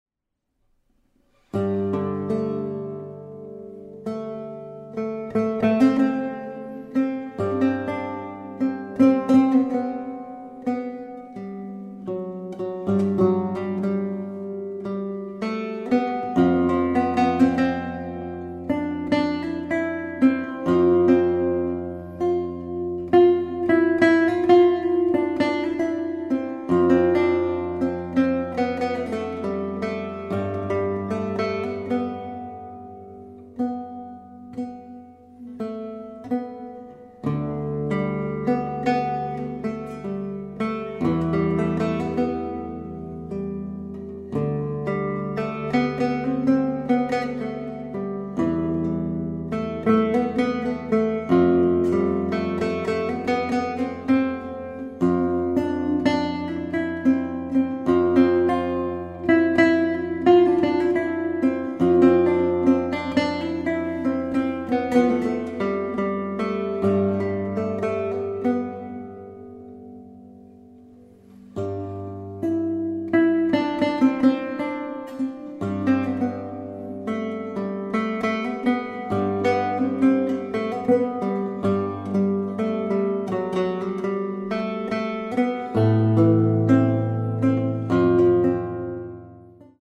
composer, lute & oud player from Japan
Contemporary , Japanese Ambience
, Lute , Relaxing / Meditative